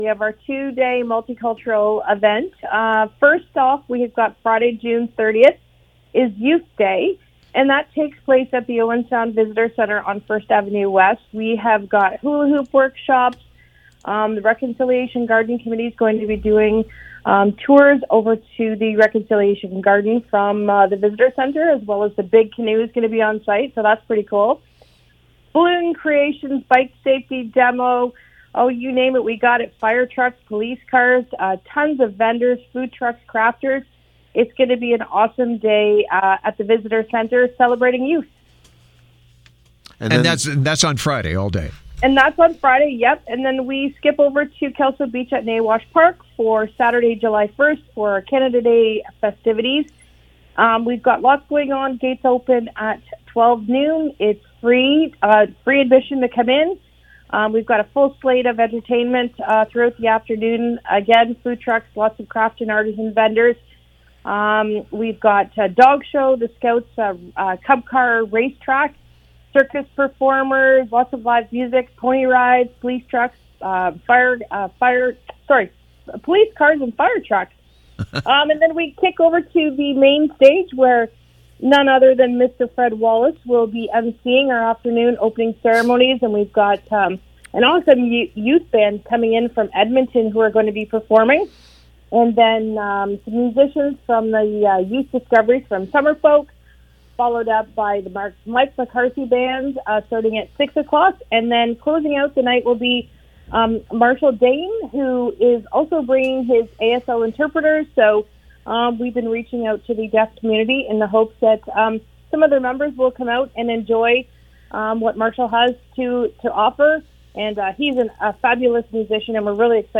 was a guest
on the CFOS Morning Show